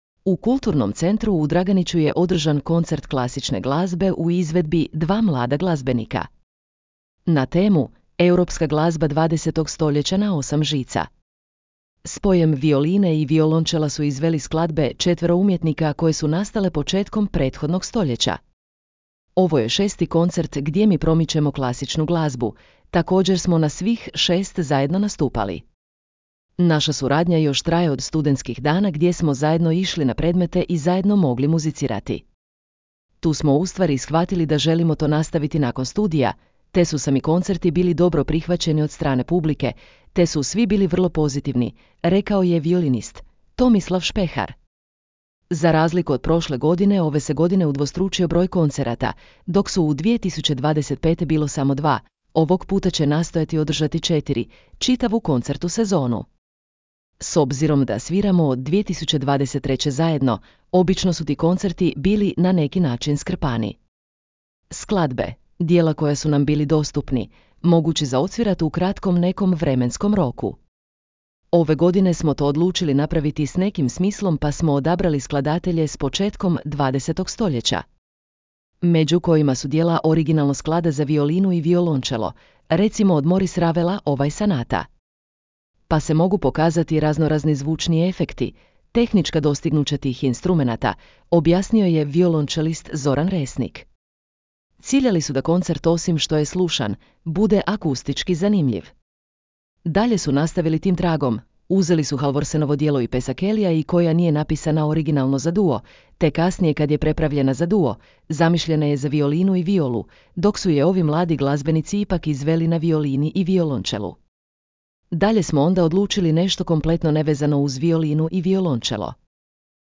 U kulturnom centru u Draganiću je održan koncert klasične glazbe u izvedbi dva mlada glazbenika.
Spojem violine i violončela su izveli skladbe četvero umjetnika koje su nastale početkom prethodnog stoljeća.
Ciljali su da koncert osim što je slušan, bude akustički zanimljiv.
Cilj im je bio približiti publici sve zvukove i tehnike, osim lijepih melodija, da se upoznaju sa svim mogućnim zvukovima klasike i svim ezoteričnim i apstraktnim pojmovima i situacijama koje nastaju.